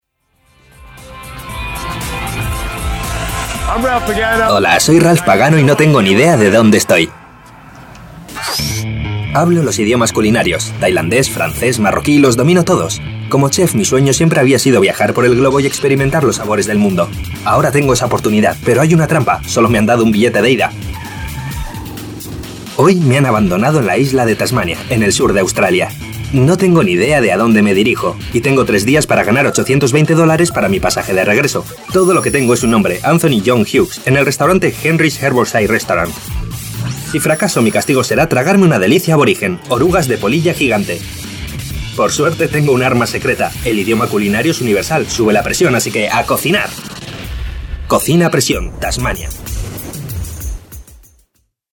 voice over actor working since 2003 for almost all Spanish TV channels.
kastilisch
Sprechprobe: Industrie (Muttersprache):